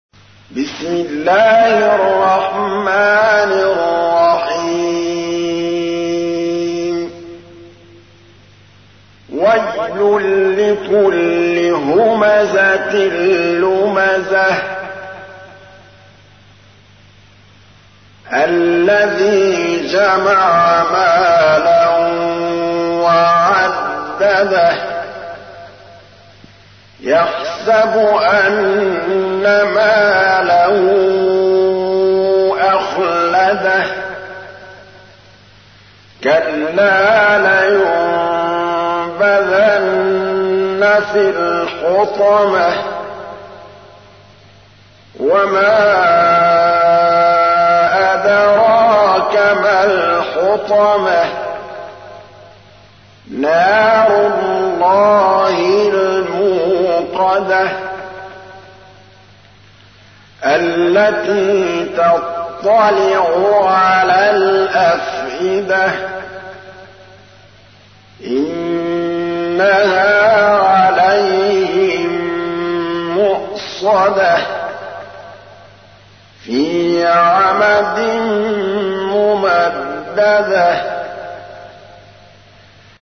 تحميل : 104. سورة الهمزة / القارئ محمود الطبلاوي / القرآن الكريم / موقع يا حسين